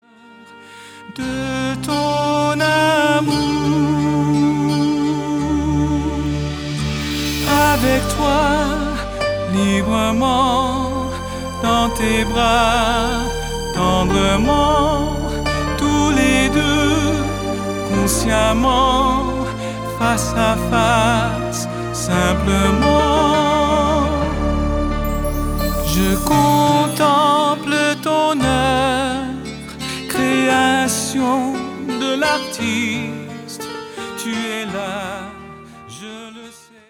production 100% studio
Ces chants de style très variés
Format :MP3 256Kbps Stéréo